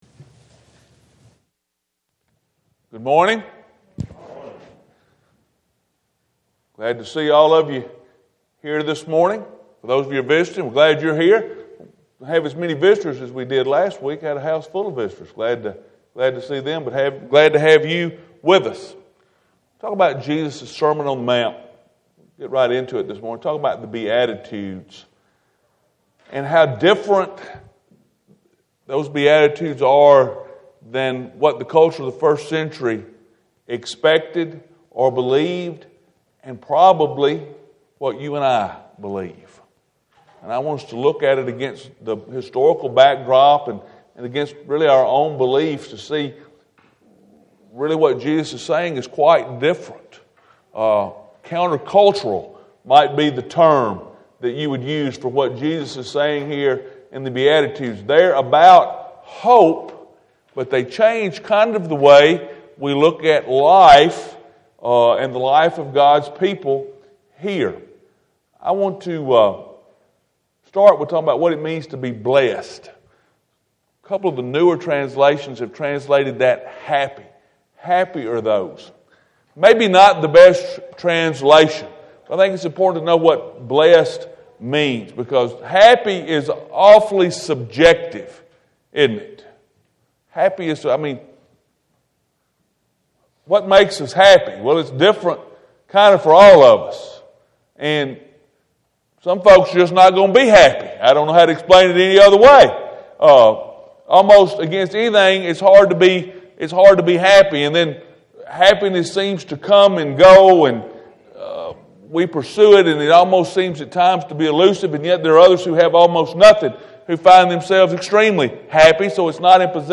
Sunday Morning ← Newer Sermon Older Sermon →